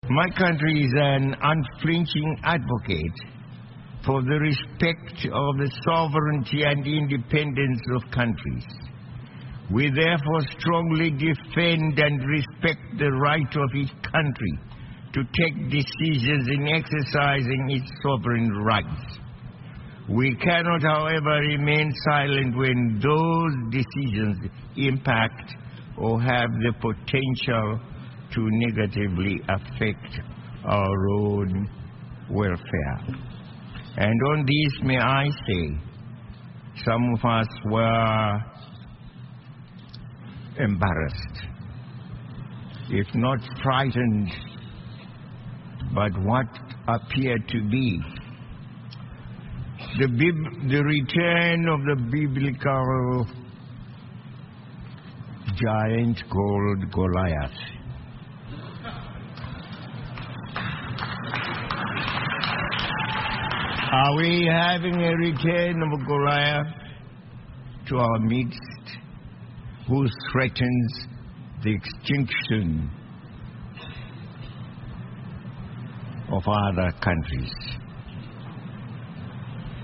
Mutungamiri wenyika, VaRobert Mugabe, vanoshora mutungamiri weAmerica, VaDonald Trump, kumusangano weUnited Nations General Assembly vachiti VaTrump vanoda kuzviita saGoliath wemubhaibheri.